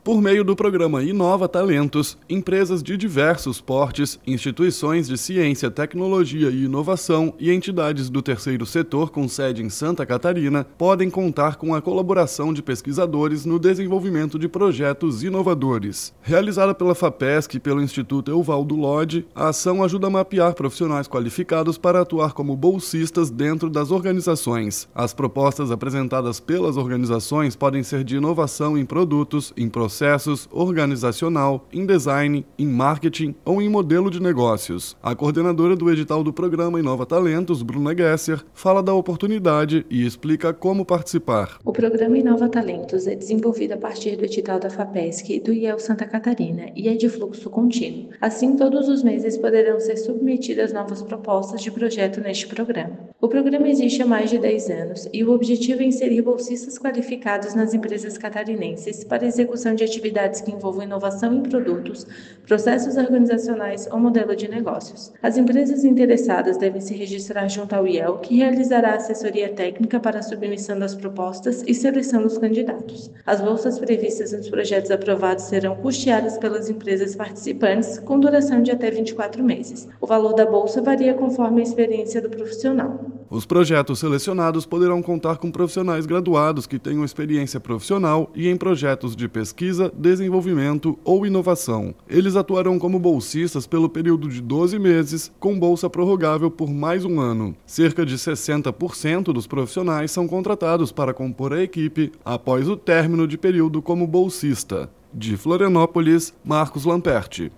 BOLETIM – Programa Inova Talentos possibilita que empresas desenvolvam projetos inovadores com apoio de profissionais qualificados